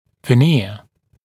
[və’nɪə][вэ’ниа]винир, коронка с фарфоровой покровной фасеткой